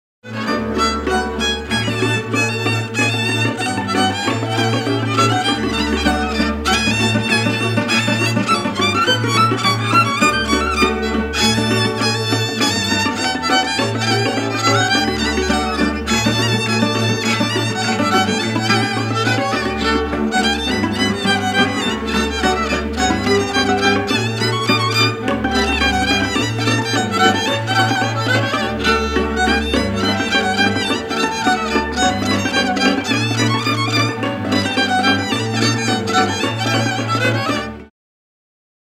Műfaj: Sűrű legényes: Barbunc
Előadásmód: Zenekar
Helység: Bonchida
prím
brácsa
bőgő
cimbalom
Etnikum: Román